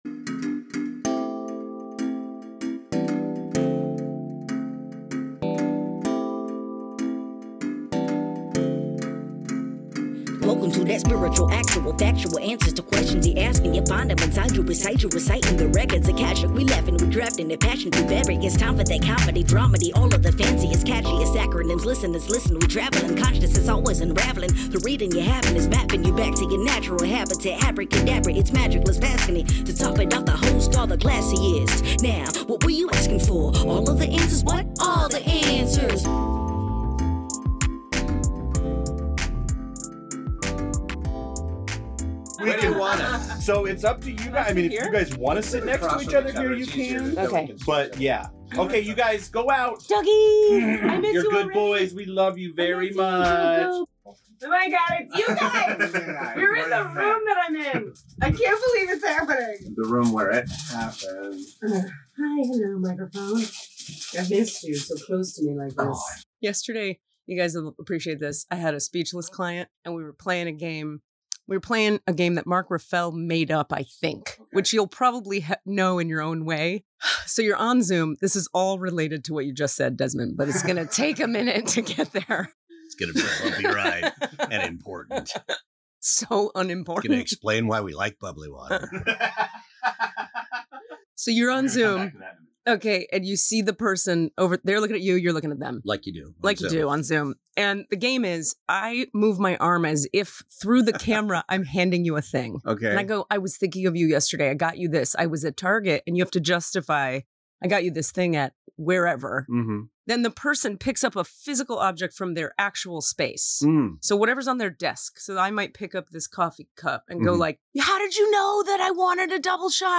It’s a live episode!!